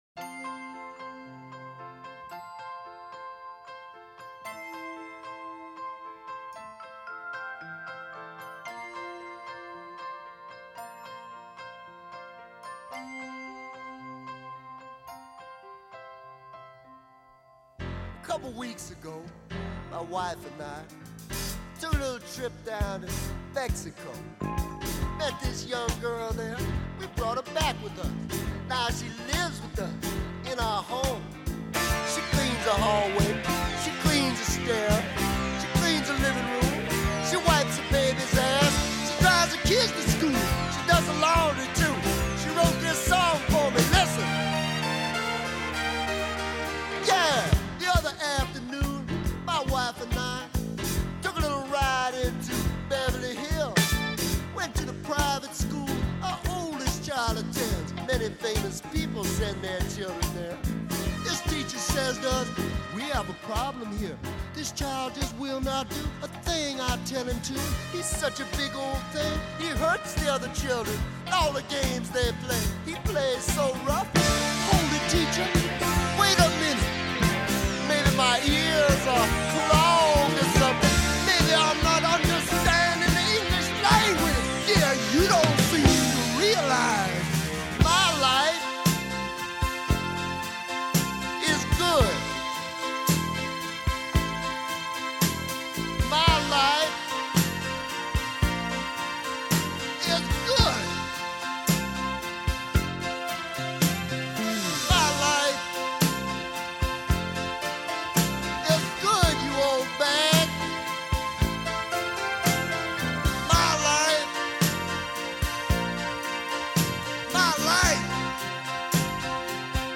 It’s quite fun, actually.